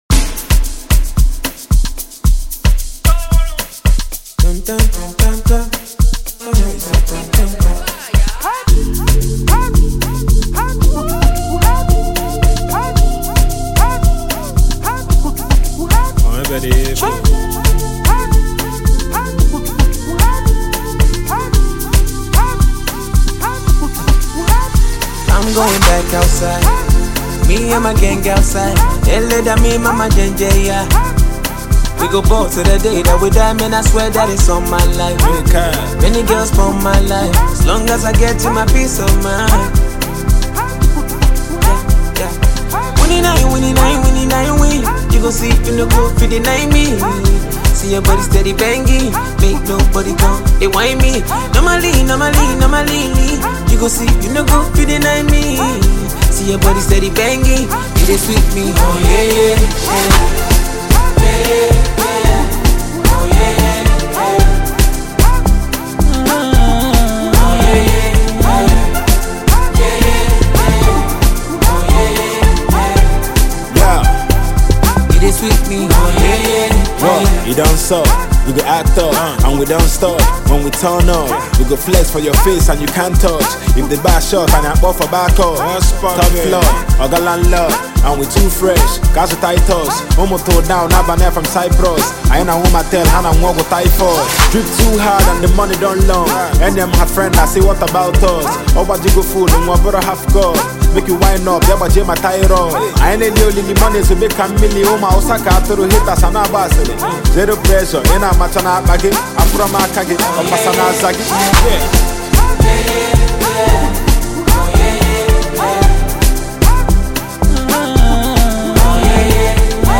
Heavyweight Nigerian rap prodigy and song writer